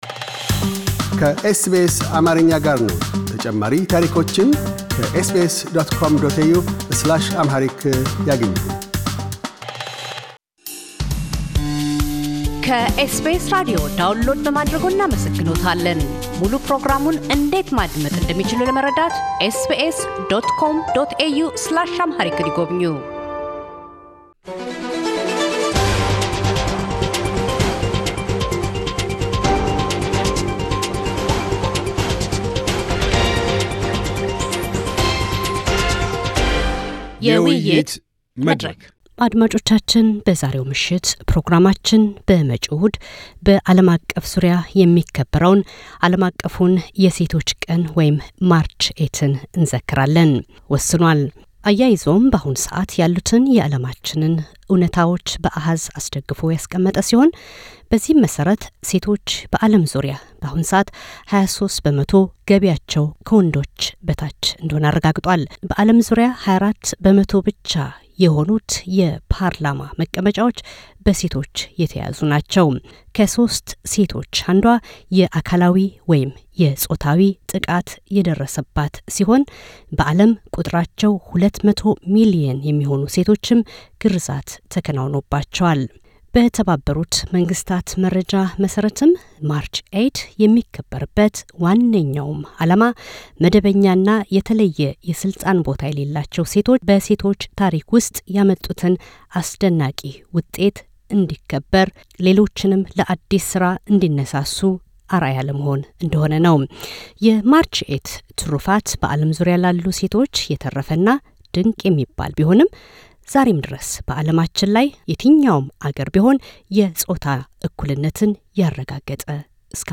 የውይይት መድረክ “ የአለም አቀፍ ሴቶች ቀን ማርች 8” - ክፍል አንድ